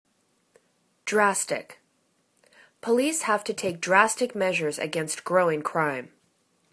dras.tic     /'drastik/    adj